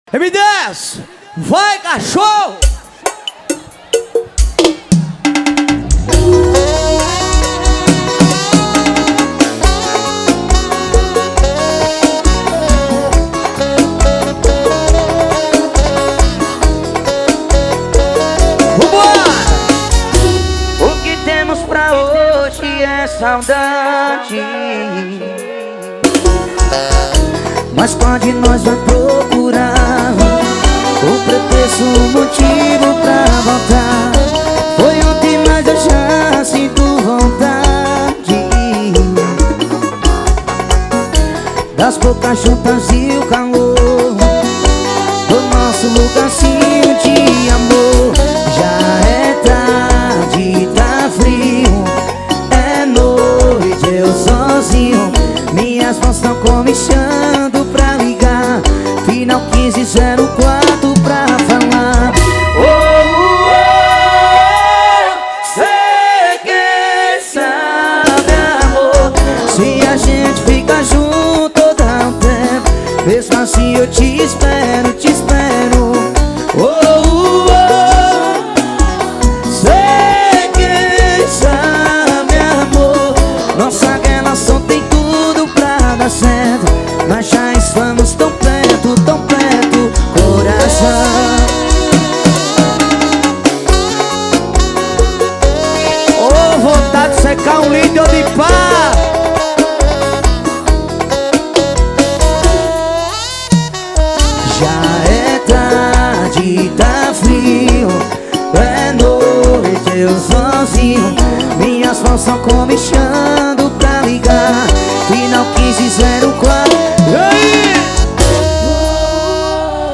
2024-12-21 09:50:39 Gênero: Arrocha Views